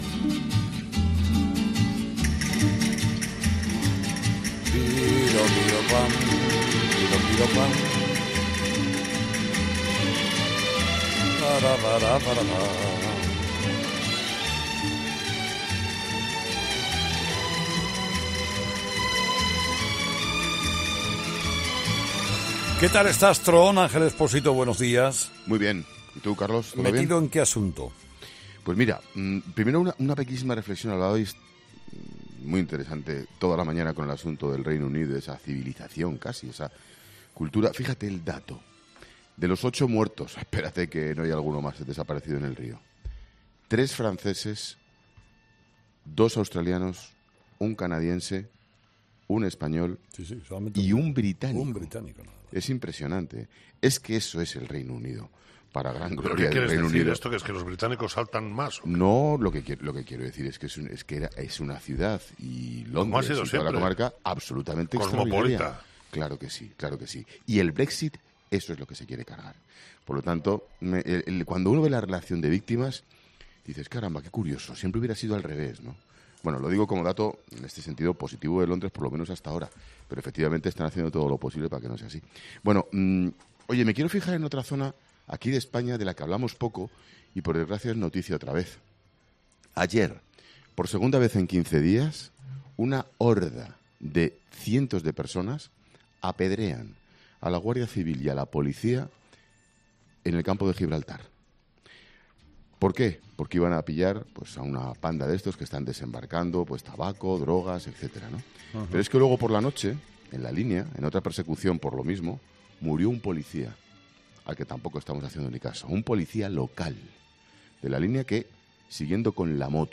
El 'Paseíllo del Tron', el análisis de Ángel Expósito en 'Herrera en COPE'